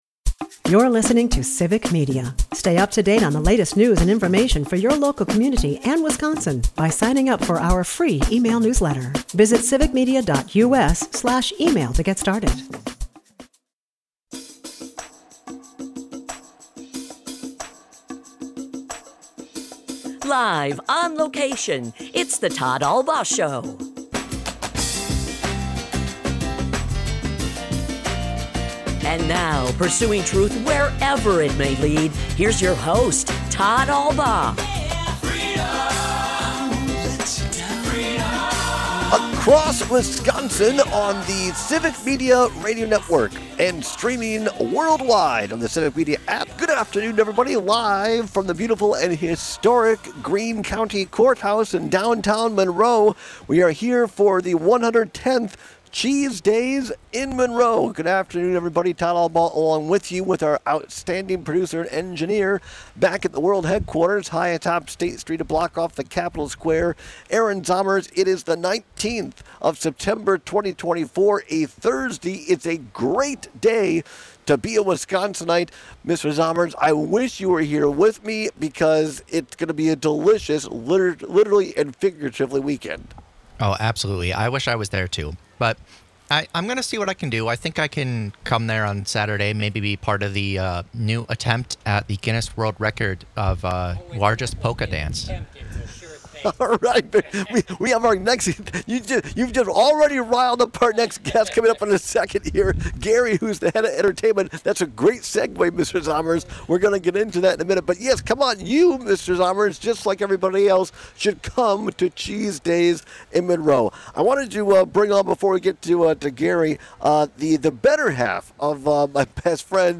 LIVE From Cheese Days In Monroe, cont. (Hour 2) - Civic Media